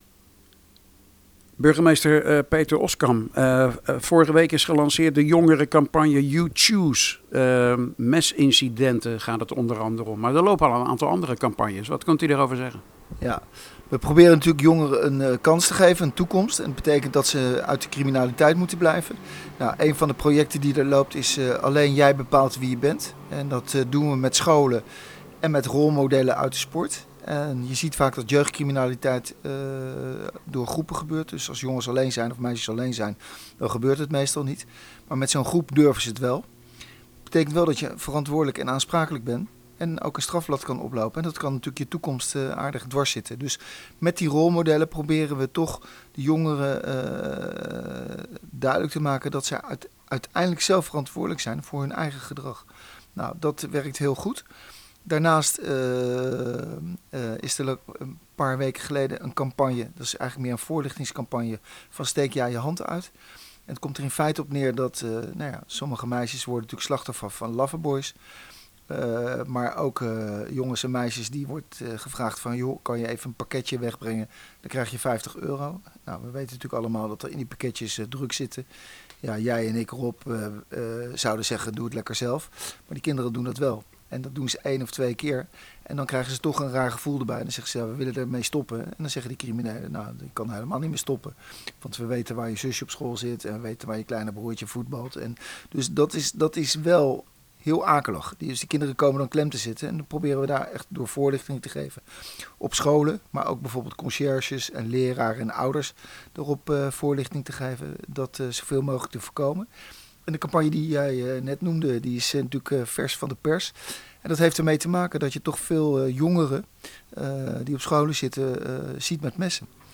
praat met burgemeester Peter Oskam